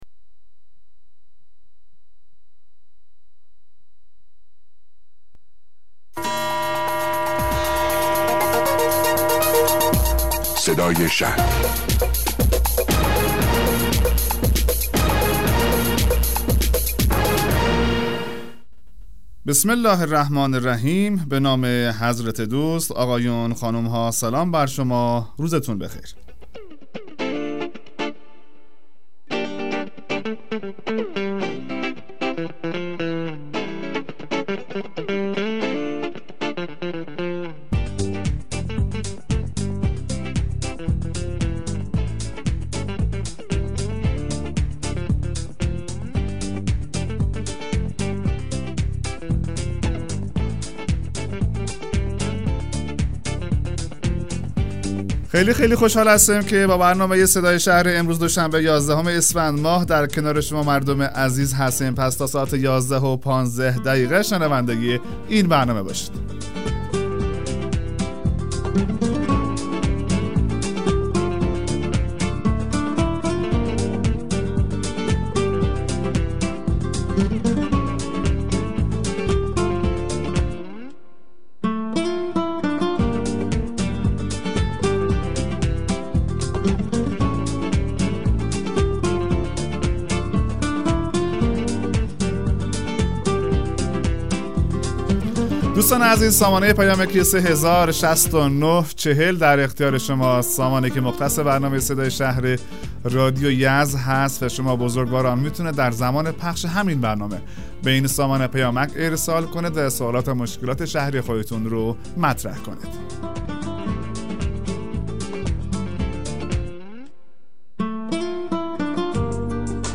مصاحبه رادیویی برنامه صدای شهر با حضور مرتضی شایق رییس کمیسیون حقوقی و نظارت شورای اسلامی شهر یزد